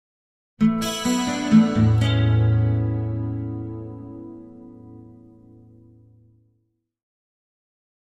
Acoustic Guitar - Music Stinger - Picked With Chorus 3